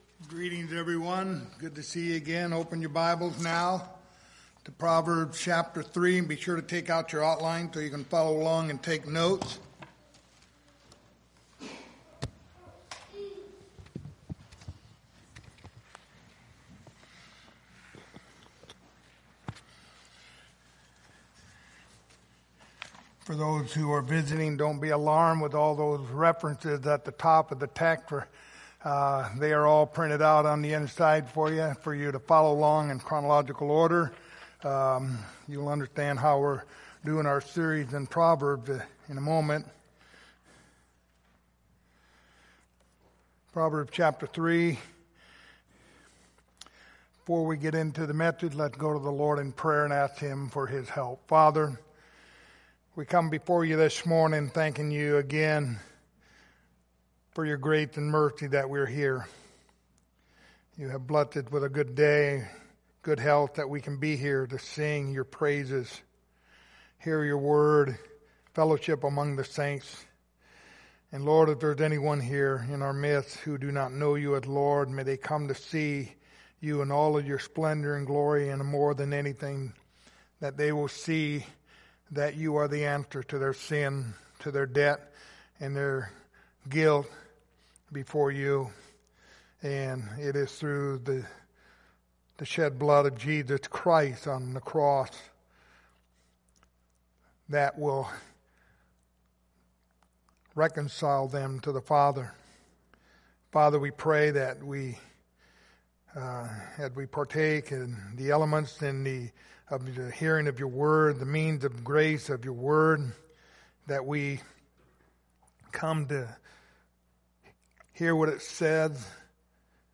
Proverbs 3:13 Service Type: Sunday Morning Topics